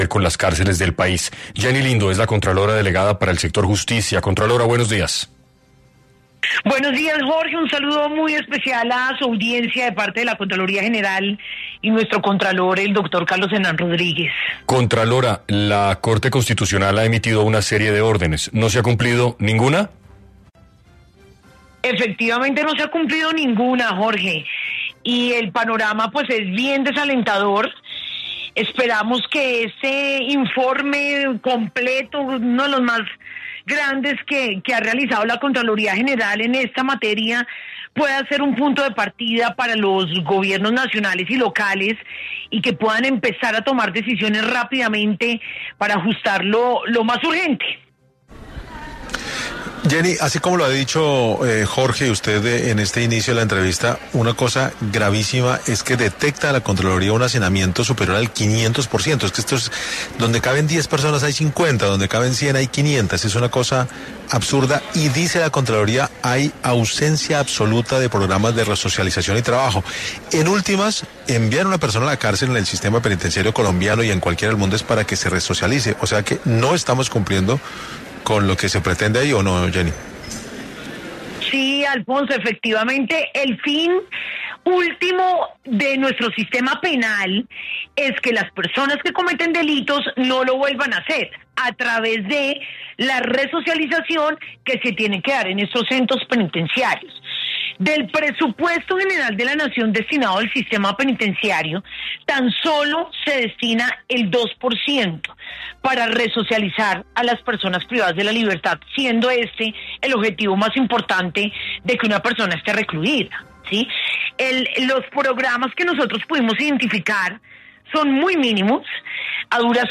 En entrevista con 6AM de Caracol Radio, Jenny Lindo, contralora delegada para el sector justicia, destacó el incumplimiento generalizado de las órdenes de la Corte Constitucional y un panorama desalentador en cuanto a la resocialización de los reclusos.